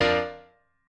admin-leaf-alice-in-misanthrope/piano34_6_024.ogg at main